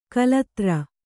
♪ kalatra